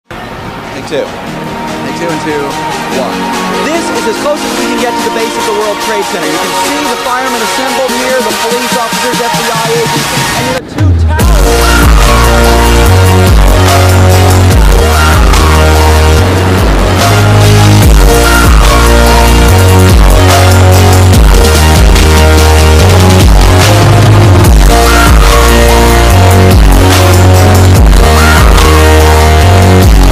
911-outro-meme-EARRAPE.mp3